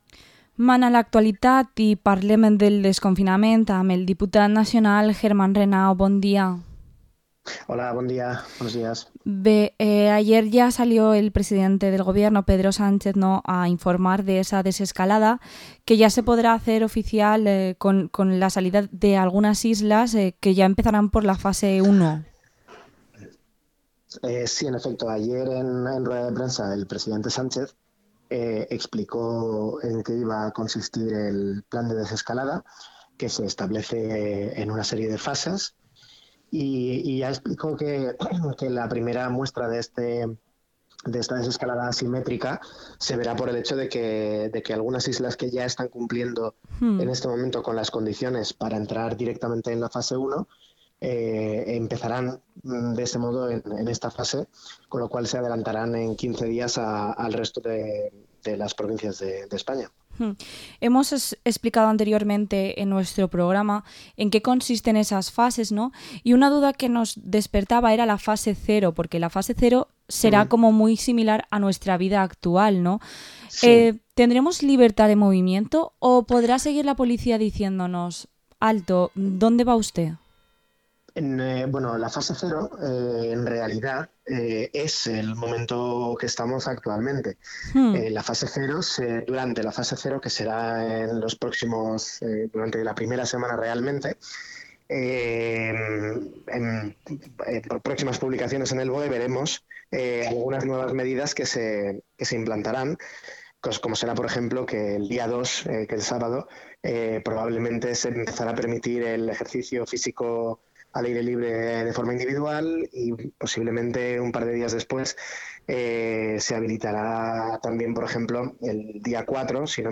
Entrevista al diputado nacional del PSPV-PSOE, Germán Renau